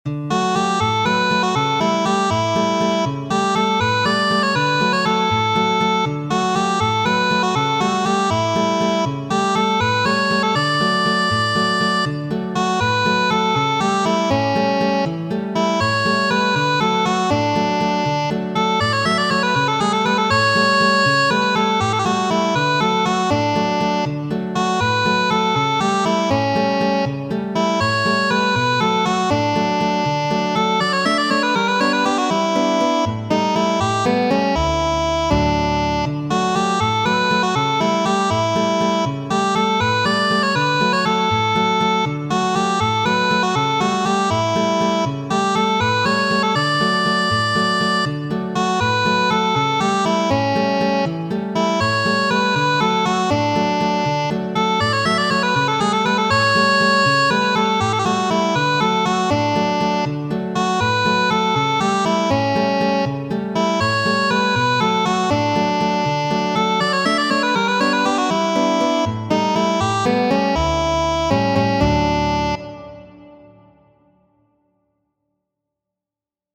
Muziko:
Italkanto, itala kanto, verkita de Petro Ludoviko Ĉejkovski kaj aranĝita por harmoniko kaj gitaro de mi mem por viaj oreloj.